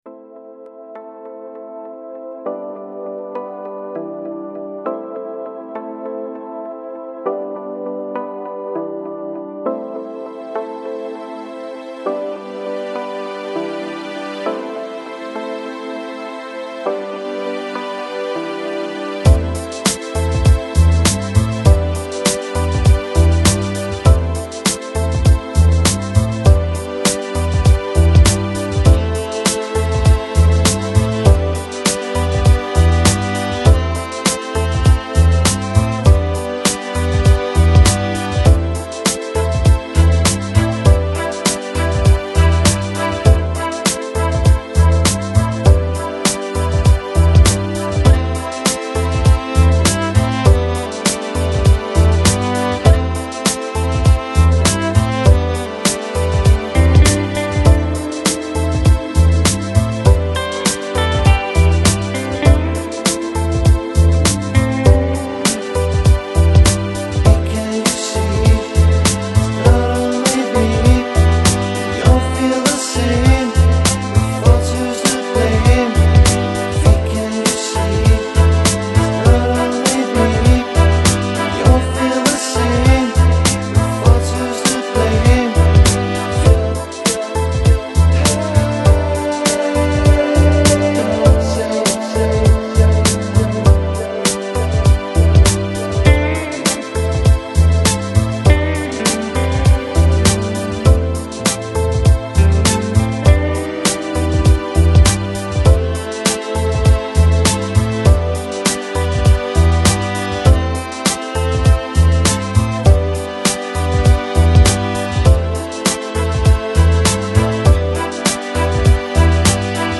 Жанр: Lounge | Chillout | Nu Jazz